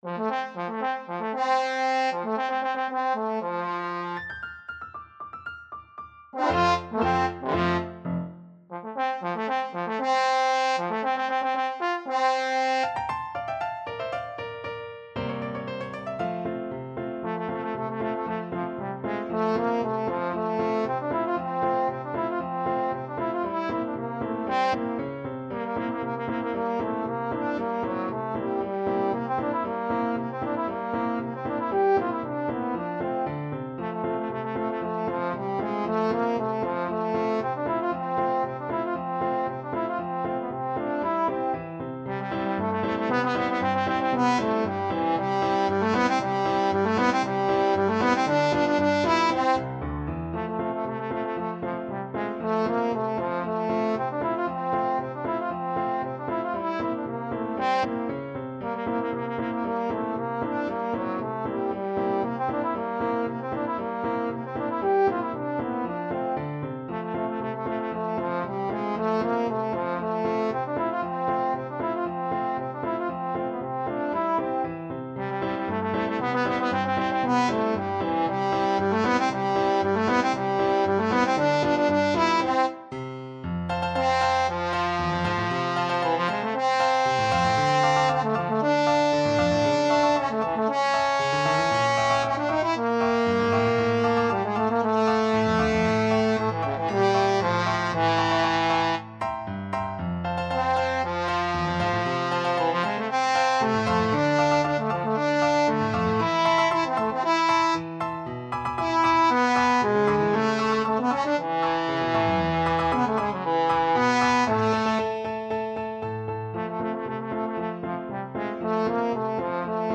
Play (or use space bar on your keyboard) Pause Music Playalong - Piano Accompaniment Playalong Band Accompaniment not yet available transpose reset tempo print settings full screen
Trombone
F major (Sounding Pitch) (View more F major Music for Trombone )
Tempo di Marcia (=116)
2/4 (View more 2/4 Music)
Classical (View more Classical Trombone Music)